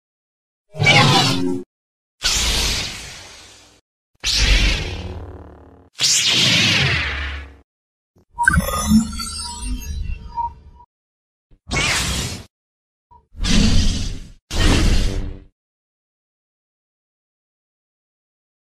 Lightsaber (Star Wars) Sound Effect MP3 Download Free - Quick Sounds
Lightsaber (Star Wars) Sound